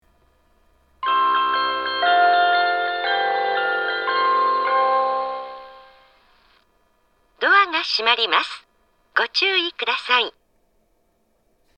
スピーカーはすべてユニペックスマリンです。
発車メロディー
一度扱えばフルコーラス鳴ります。
メロディーは旧来からのバージョンを使用しています。